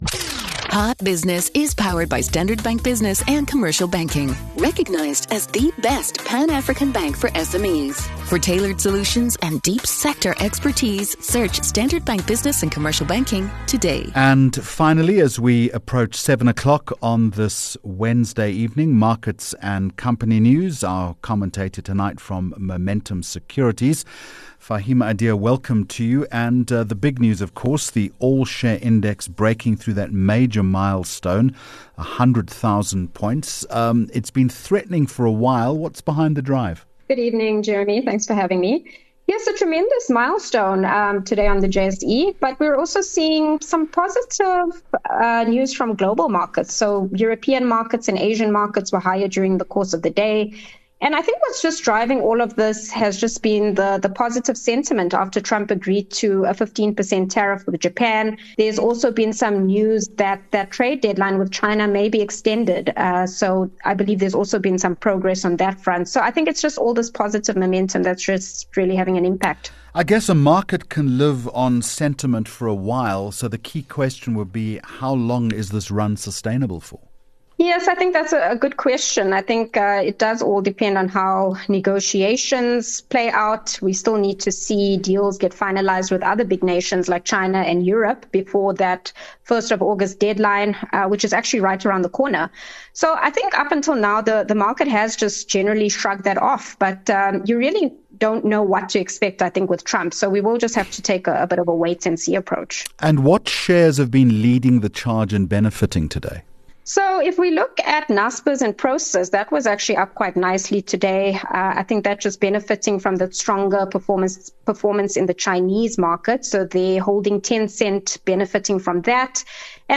23 Jul Hot Business Interview